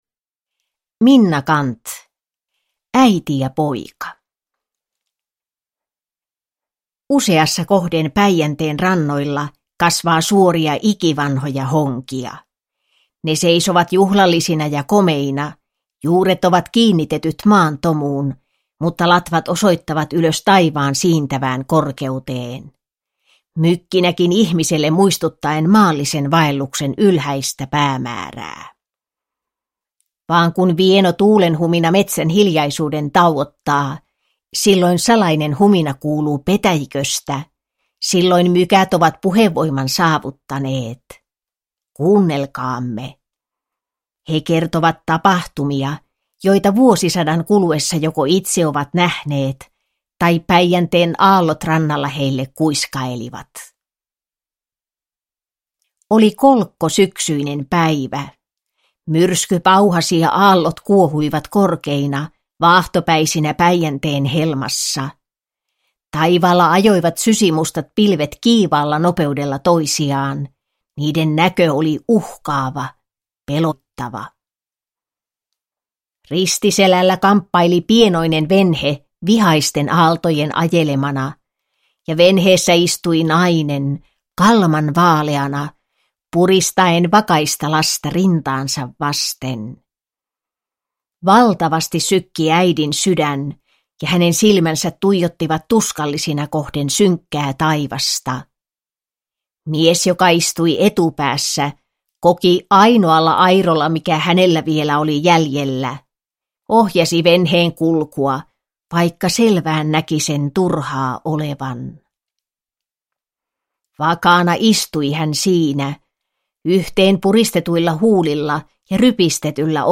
Äiti ja poika - Päivä koittaa / Ljudbok